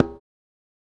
Kicks
African Drum_02.wav